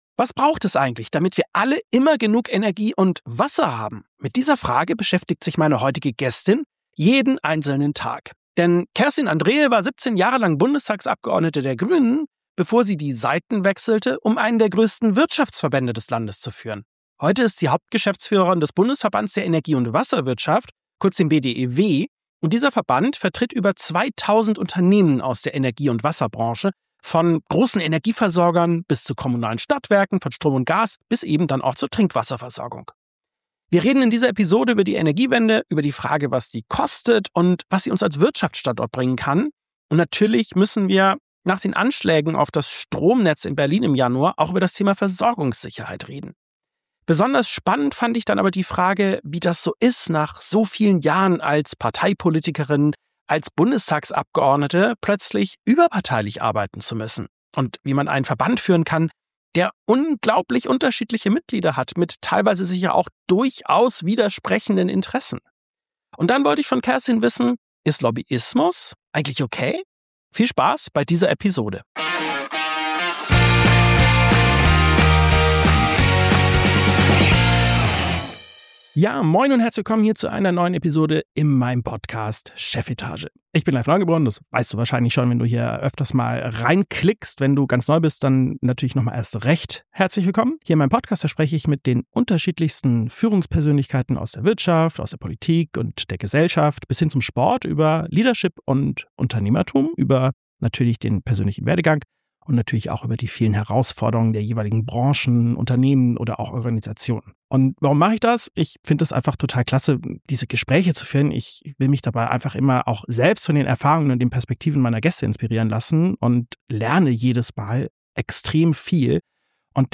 Wie sichern wir unsere Energie? Kerstin Andreae, Bundesverband Energie- und Wasserwirtschaft - 95 ~ Chefetage - CEOs, Unternehmer und Führungskräfte im Gespräch Podcast